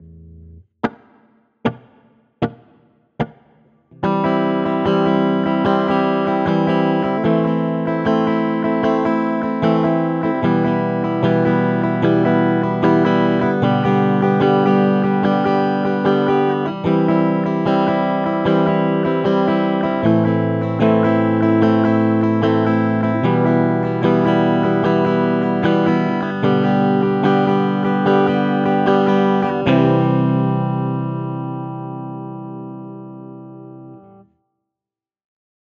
Rythme: double croche - croche - double croche
Audio : 4 X BH(B)H sur chaque accord (DO lAm MIm SOL)